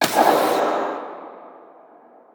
JackHammer_far_01.wav